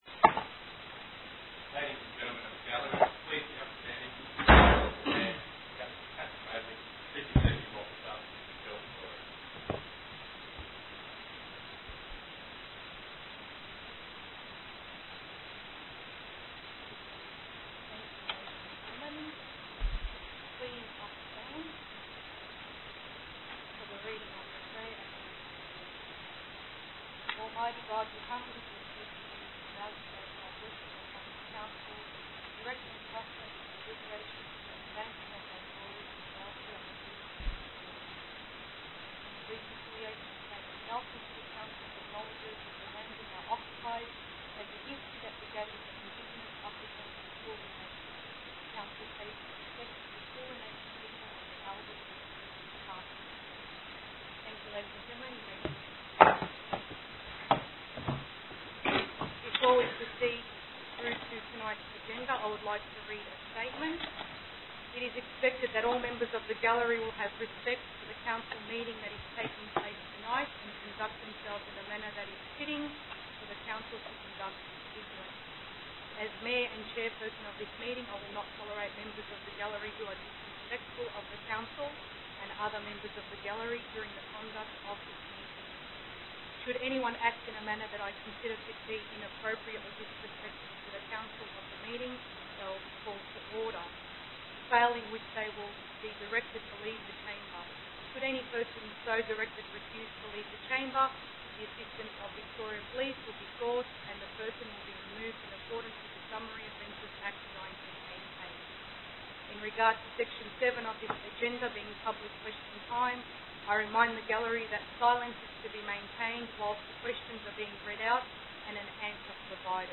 5 February 2013 Ordinary council meeting
council_meeting_5_feb_2013.mp3